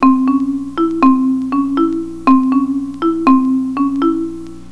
We can use loudness to accentuate one of the patterns, if you select an instrument on your synthesiser which is sensitive for loudness.